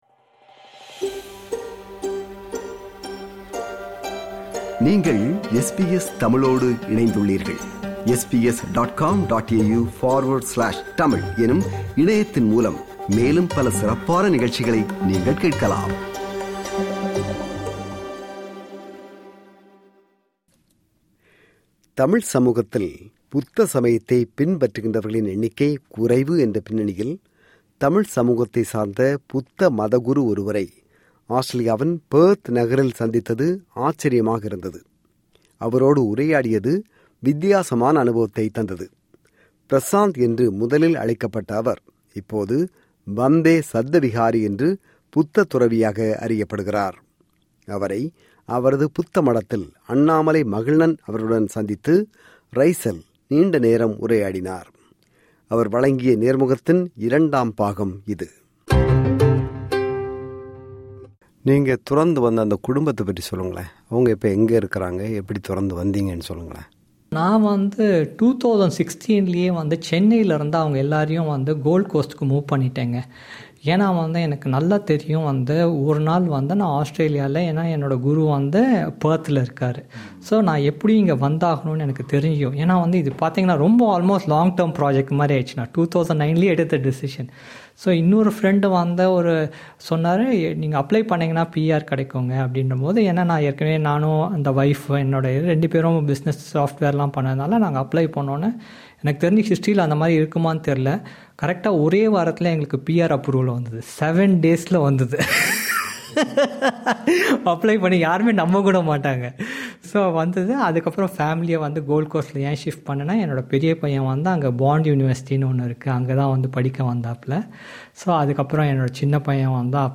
அவர் வழங்கிய நேர்முகத்தின் இரண்டாம் பாகம்.